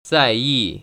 [zài//yì] 짜이이  ▶